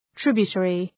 Προφορά
{‘trıbjə,terı}